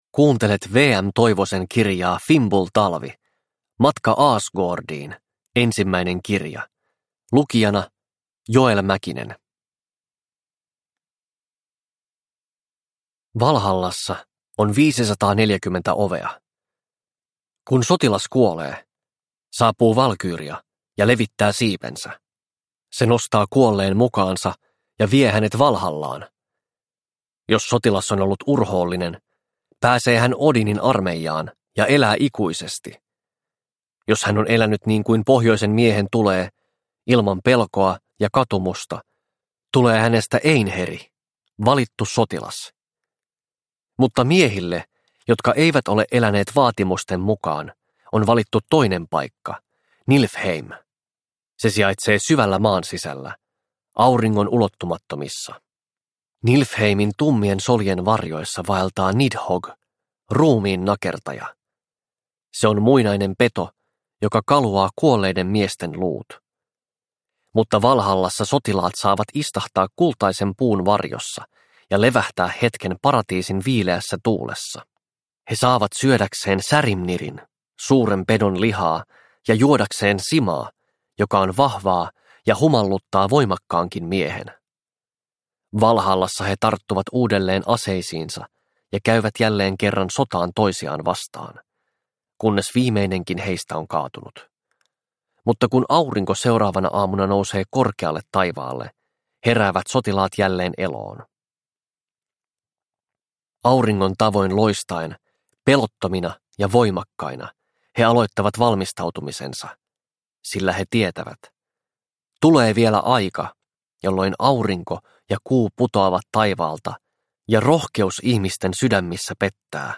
Fimbul-talvi – Ljudbok – Laddas ner